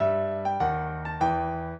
piano
minuet9-2.wav